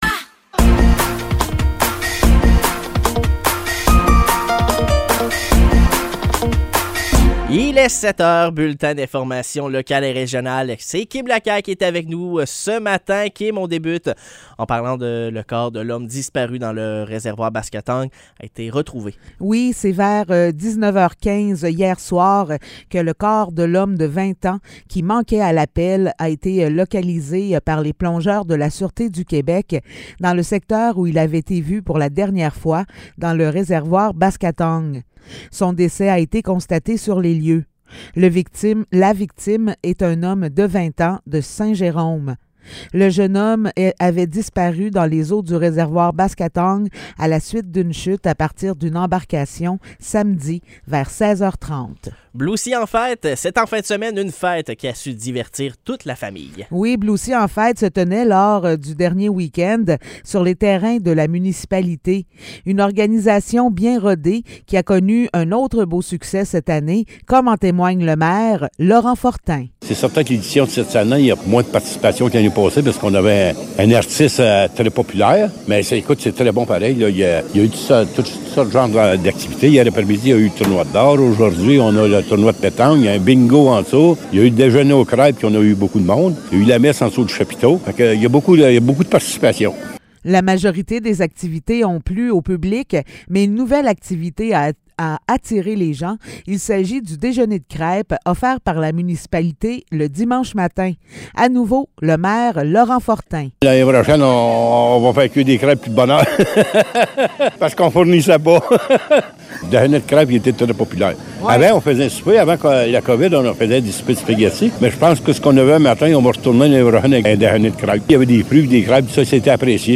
Nouvelles locales - 10 juillet 2023 - 7 h